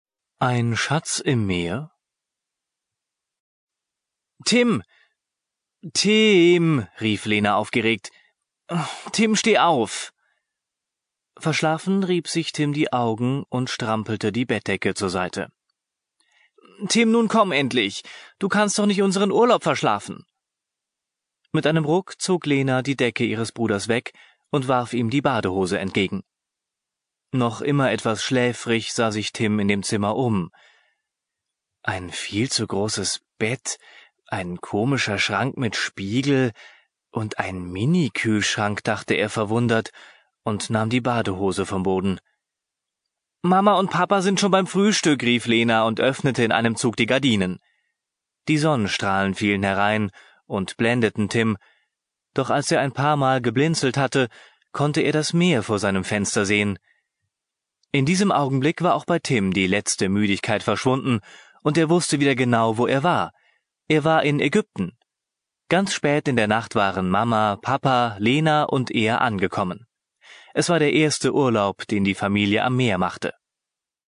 Alle Geschichten sind von deutschen Muttersprachlern gesprochen, um den Kindern ein Gefühl für die Sprachmelodie und Aussprache zu vermitteln.
Alle Geschichten sind von ausgebildeten Sprechern gesprochen, um den Kindern ein Gefühl für die Sprachmelodie und Aussprache des Hochdeutschen zu vermitteln.